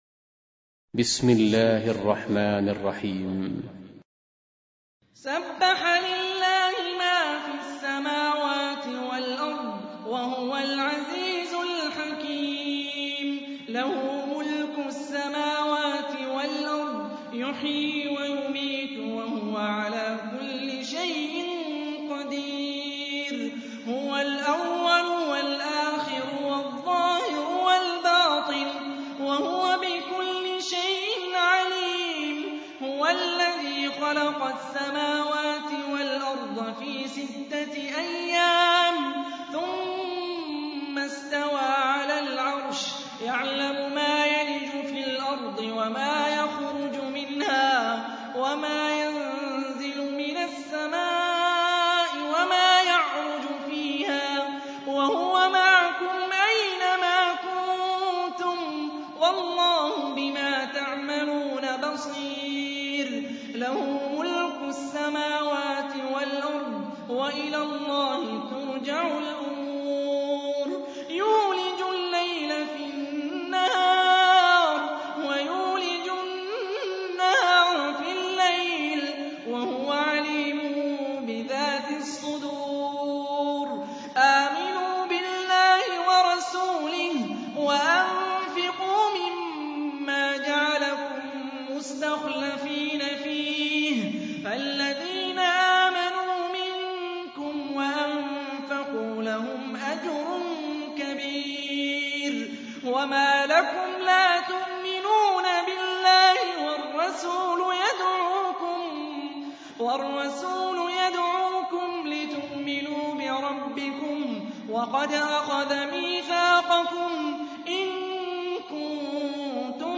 Surah Repeating تكرار السورة Download Surah حمّل السورة Reciting Murattalah Audio for 57. Surah Al-Had�d سورة الحديد N.B *Surah Includes Al-Basmalah Reciters Sequents تتابع التلاوات Reciters Repeats تكرار التلاوات